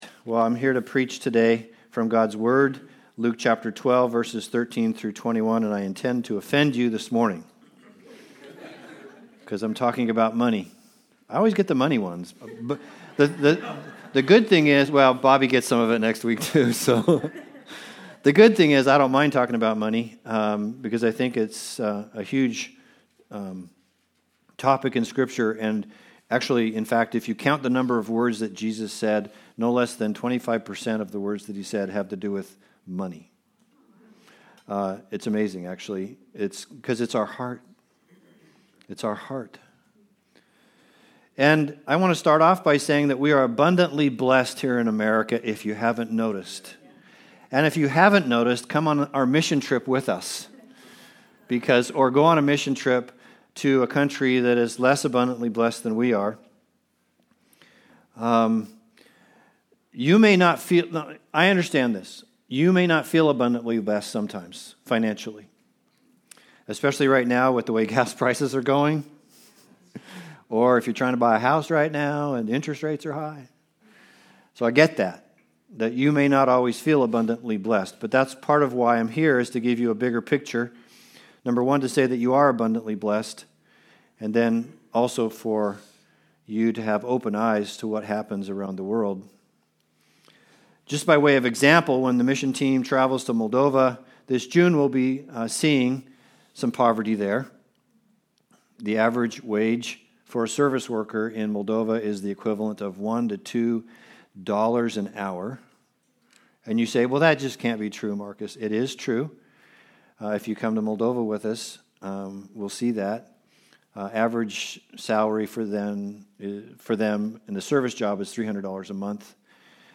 Passage: Luke 12:13-21 Service Type: Sunday Service